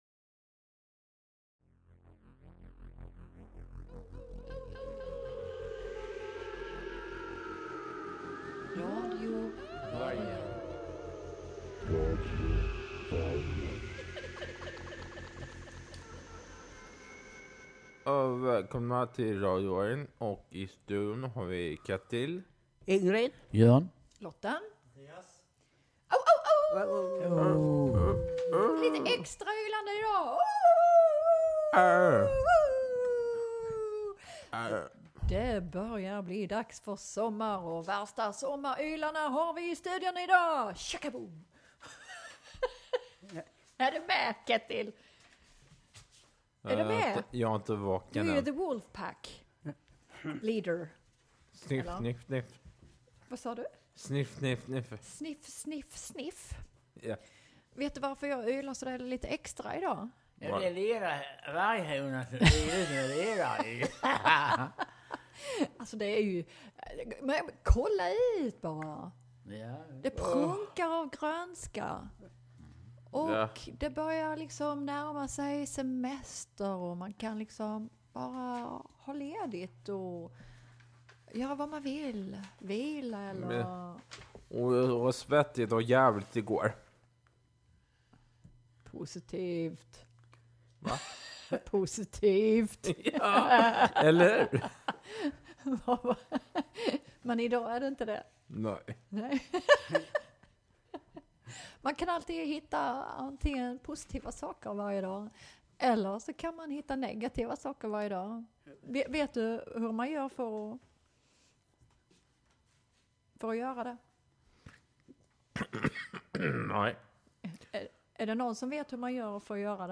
Vårens sista sändning av Radio Vargen är här. Temat för dagen är sommar. Vad är sommar för radiopratarna?